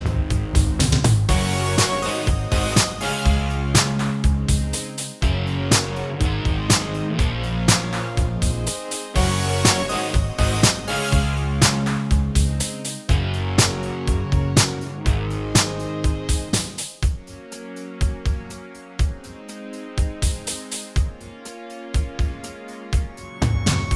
Minus Guitars Pop (1980s) 4:35 Buy £1.50